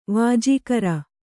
♪ vājīkara